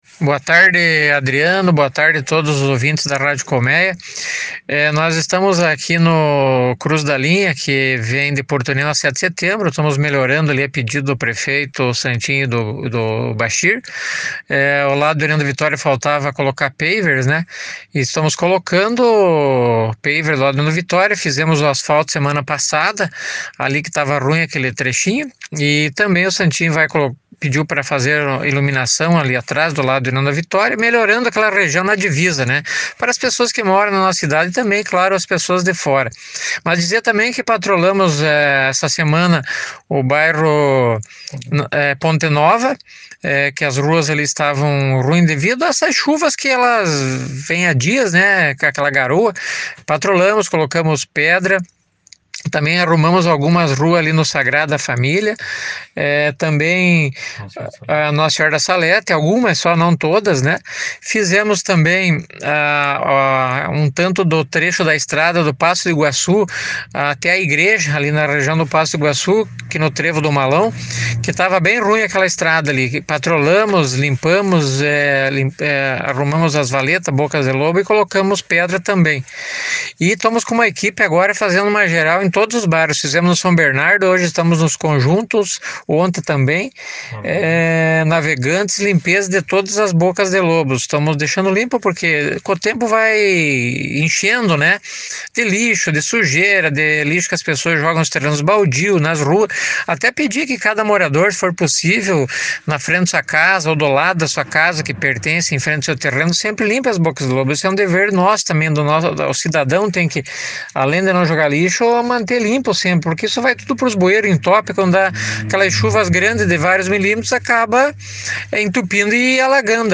Melhorias foram realizadas em toda União da Vitória pela Secretaria de Obras nessa semana. Segundo o secretário Aloísio Salvatti, em entrevista neste sábado, 18 de julho, além do centro, equipes da secretaria vem fazendo melhorias em todos os bairros.